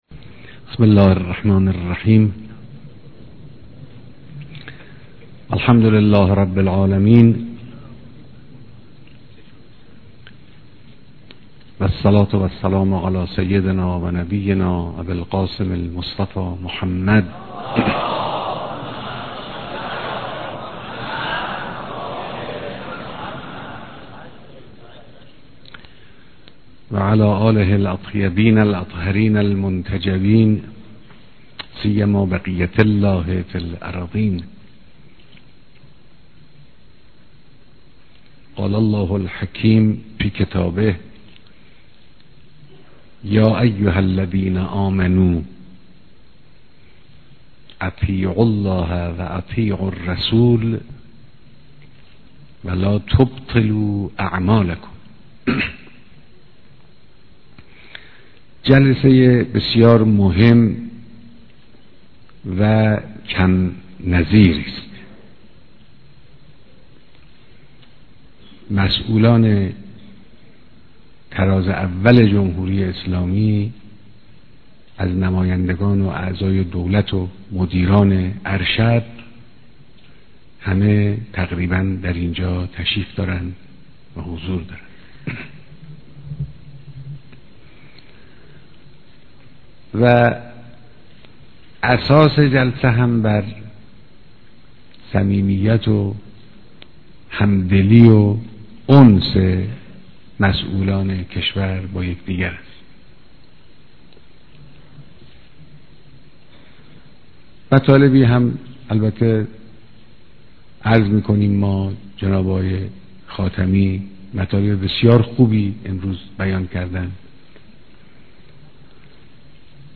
بيانات در ديدار مسؤولان نظام